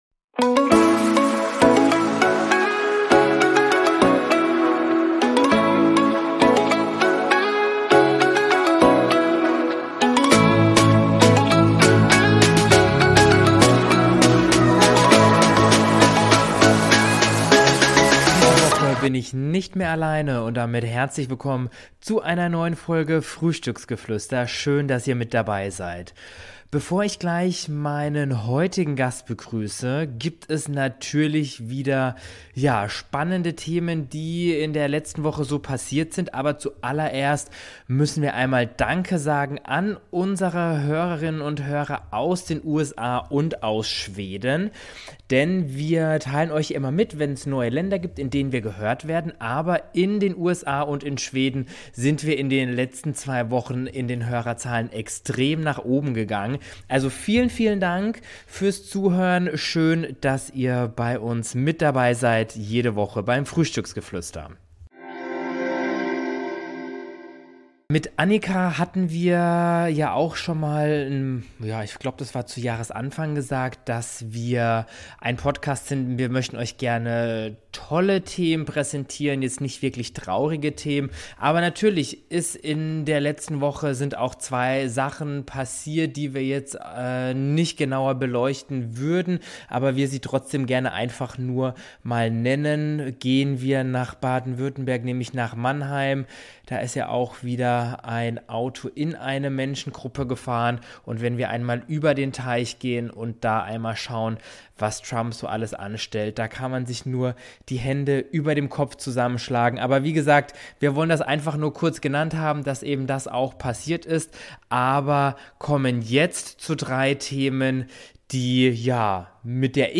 Hört ´rein und freut Euch auf ein tolles Gespräch.